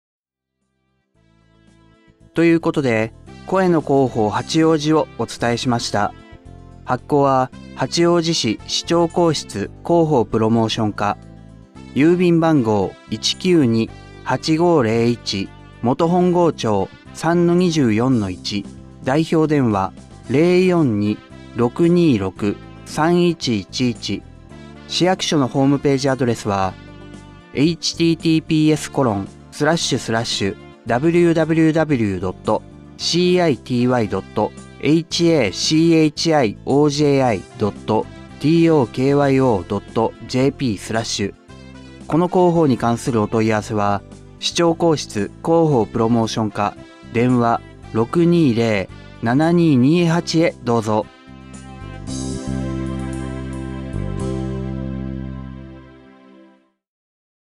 「声の広報」は、視覚障害がある方を対象に「広報はちおうじ」の記事を再編集し、音声にしたものです。